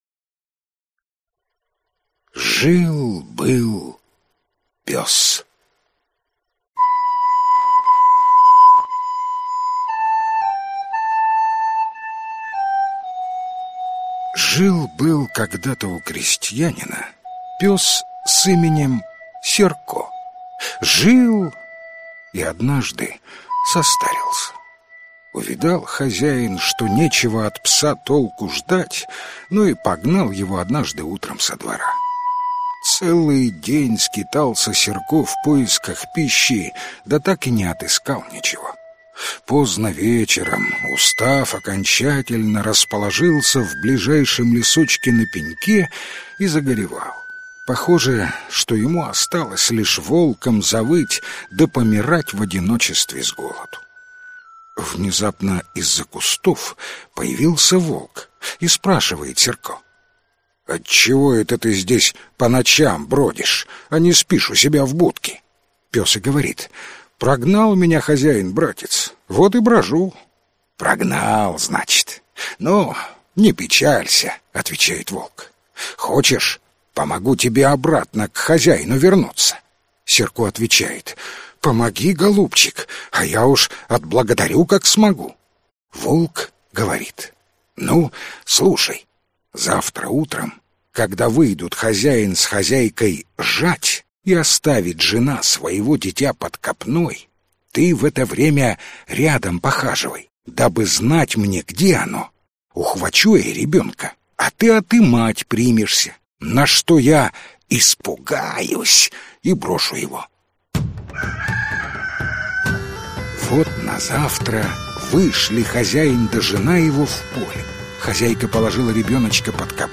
Аудиокнига Украинские волшебные сказки | Библиотека аудиокниг